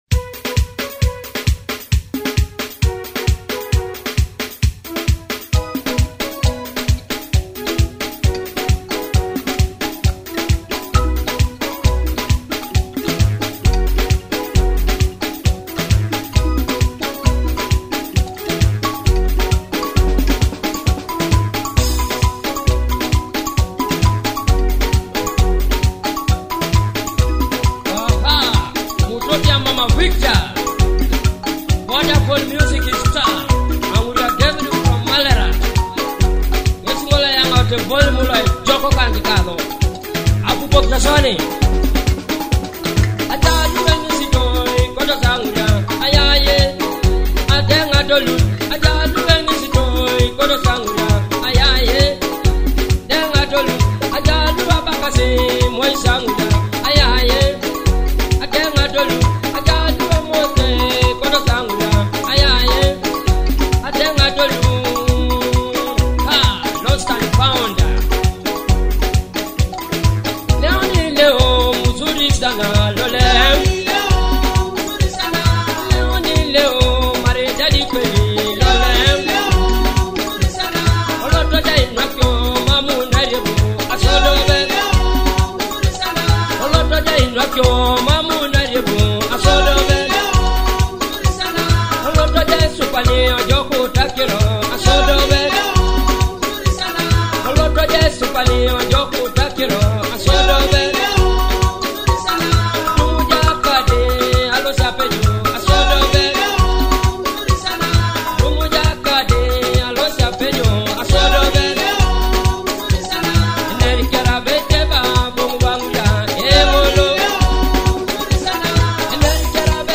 Akogo (thumb piano) and Adungu (arched harp).